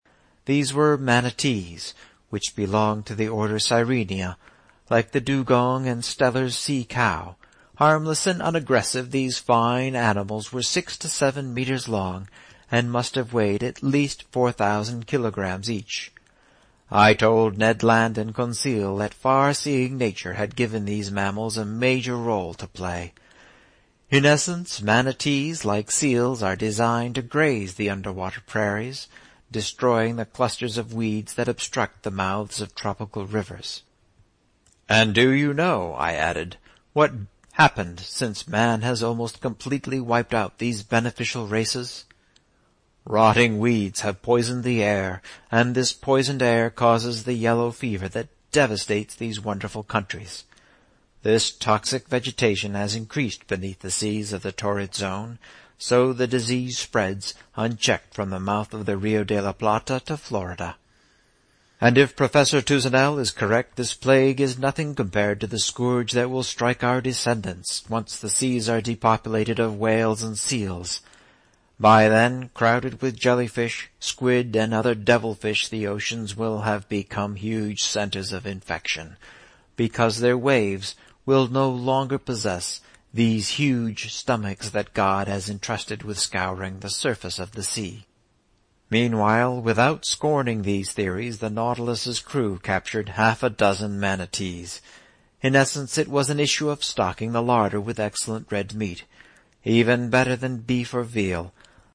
英语听书《海底两万里》第488期 第30章 从合恩角到亚马逊河(13) 听力文件下载—在线英语听力室
在线英语听力室英语听书《海底两万里》第488期 第30章 从合恩角到亚马逊河(13)的听力文件下载,《海底两万里》中英双语有声读物附MP3下载